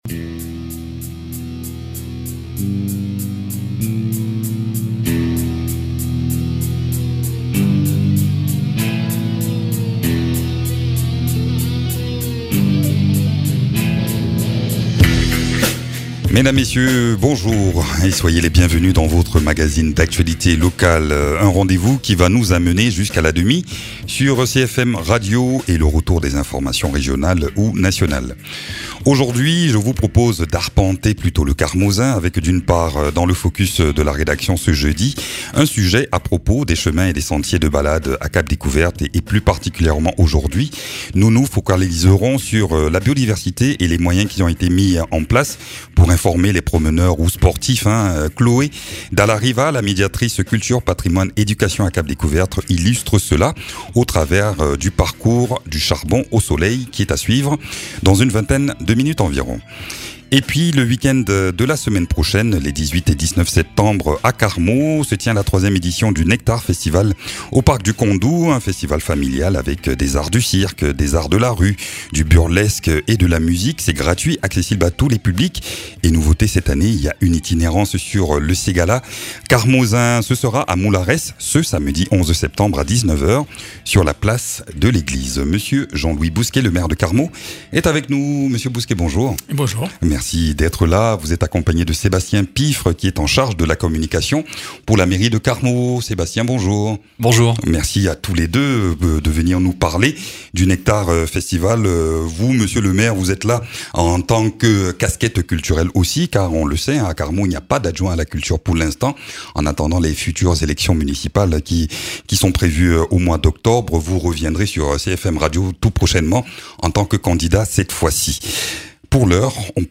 Jean-Louis Bousquet, maire de Carmaux